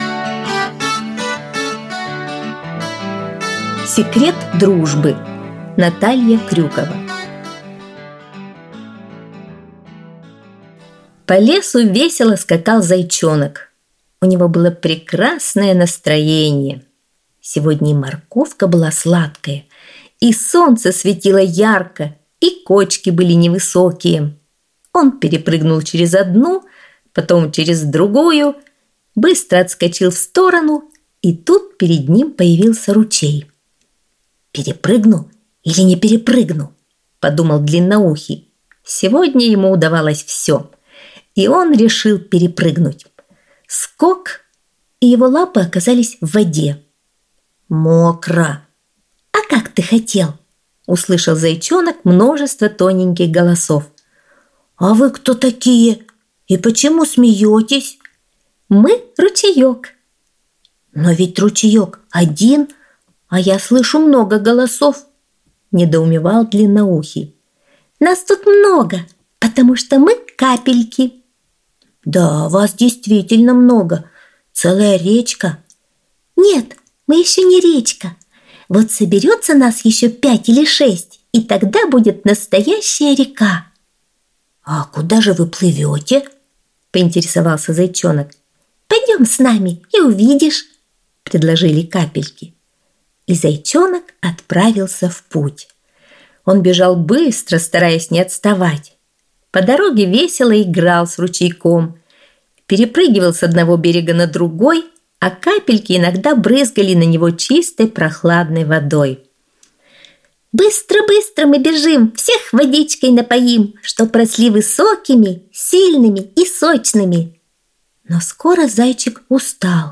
Аудиосказка «Секрет дружбы»